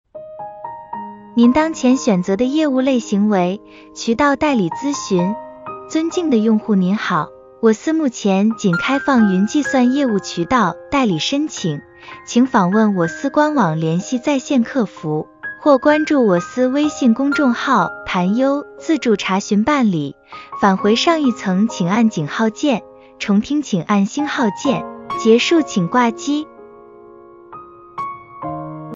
IVR音频录制试听案例：